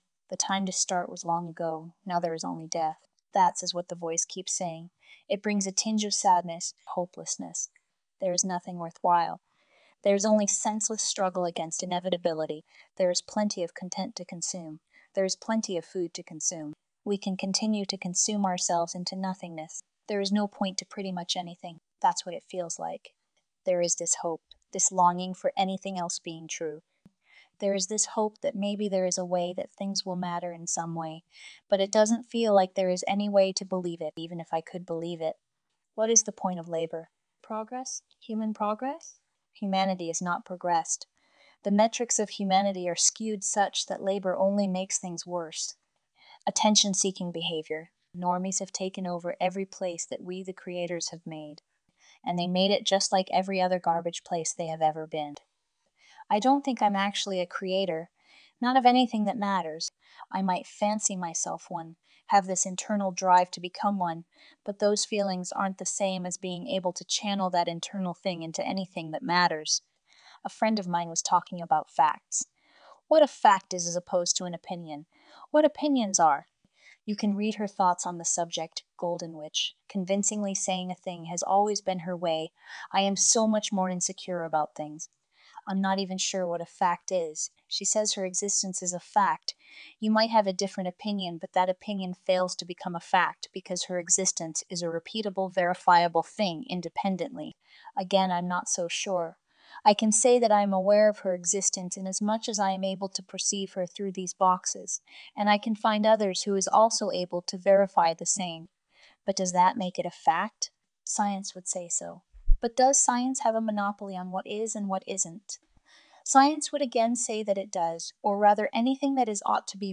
That's is what the voice keeps saying. It brings a tinge of sadness, Hopelessness.
art black denpa optimism safe